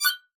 Cool UI Button 14.wav